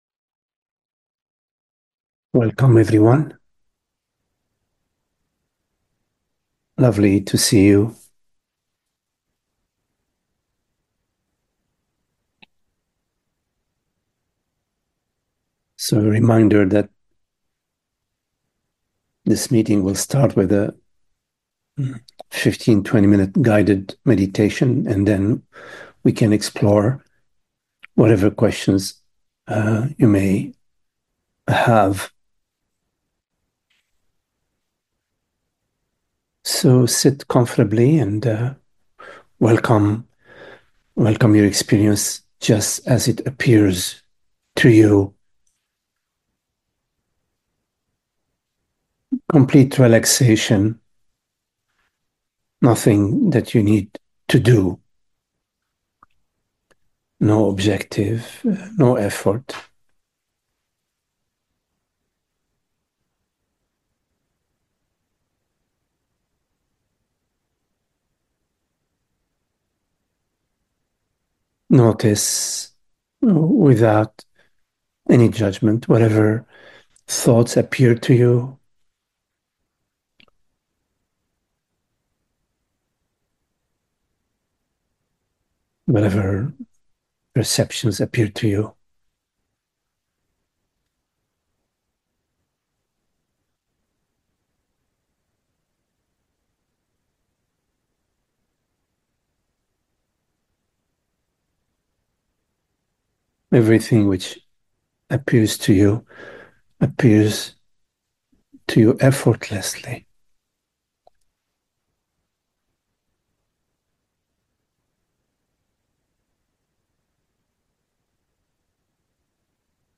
So a reminder, that this meeting will start with a 15, 20 minute guided meditation, and then we can explore whatever questions you may have.